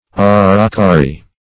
Search Result for " aracari" : The Collaborative International Dictionary of English v.0.48: Aracari \A`ra*[,c]a"ri\, n. (Zool.)